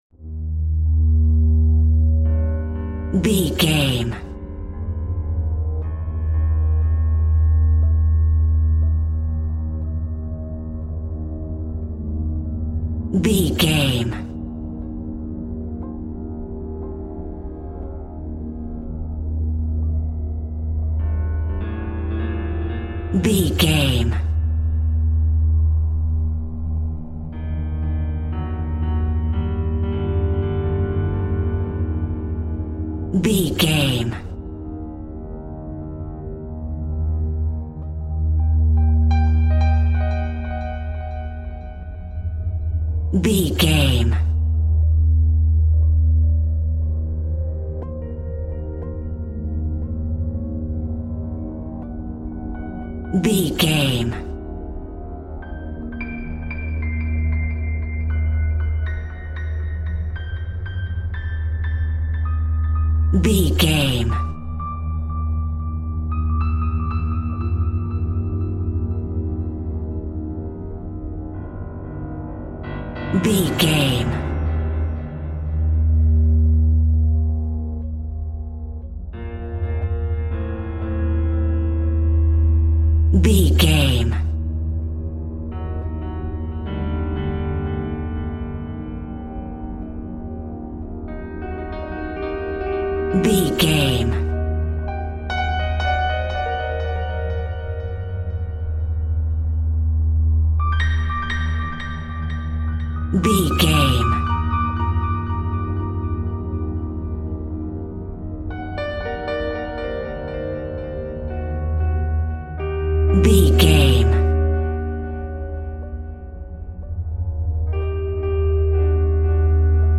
Horror Haunting Music Cue.
Atonal
Slow
tension
ominous
eerie
piano
synthesiser
strings
ambience
pads